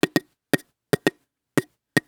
Tiny African Drum Loop 2 (115BPM).wav